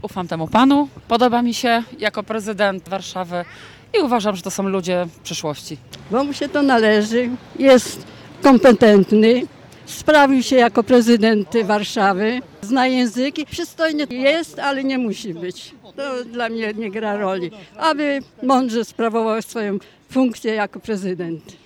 Mieszkańcy Stargardu, składając swoje podpisy poparcia, tłumaczyli, dlaczego ich zdaniem to właśnie Trzaskowski, a nie któryś z kontrkandydatów, jest najlepszym wyborem na najwyższy urząd w państwie.